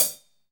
HH HH271.wav